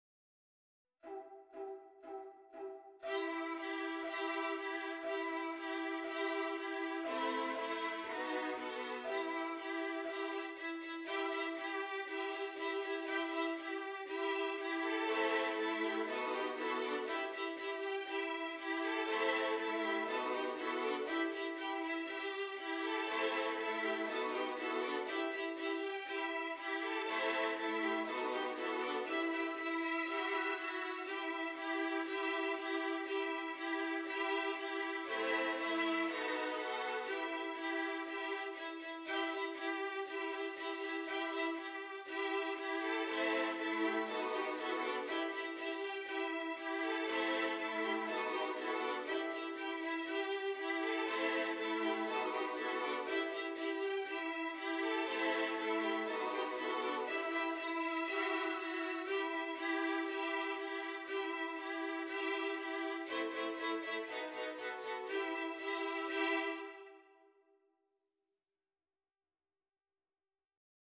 to play along with part 5 (open strings).
• Violin 1': Main melody, one octave higher
• Violin 2: Harmony/accompaniment